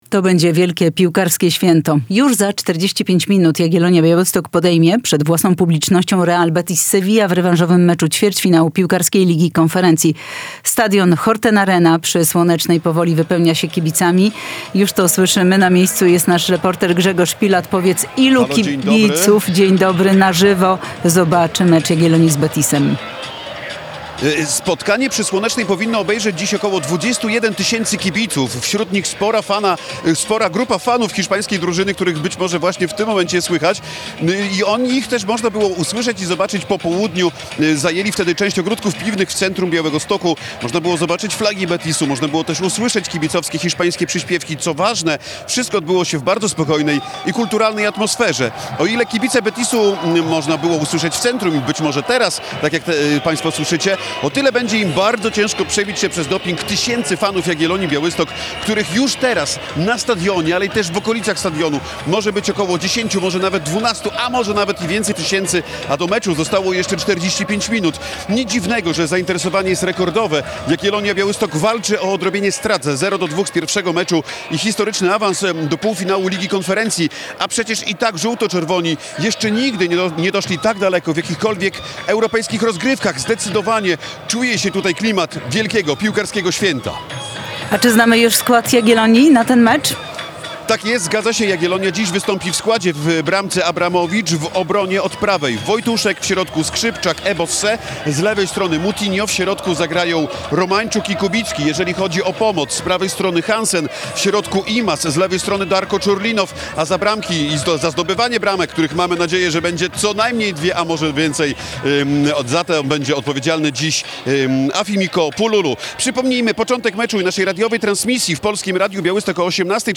Wejście live przed meczem Jagiellonia - Betis Sewilla